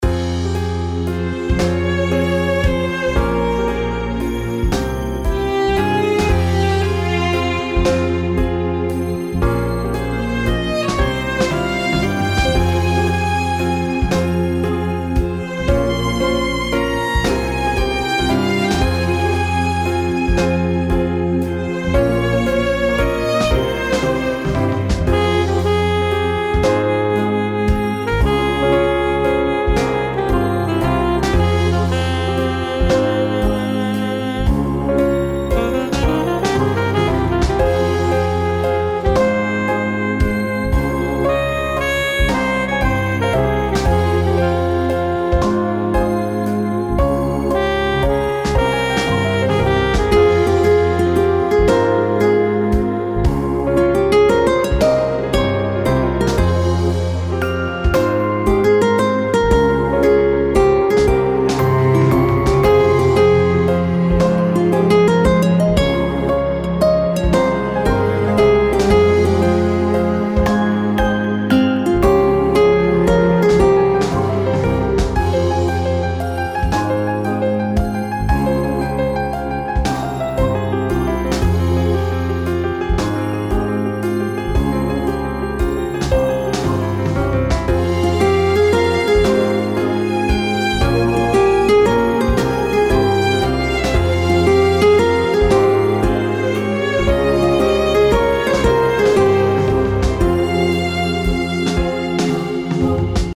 【対局時のBGM】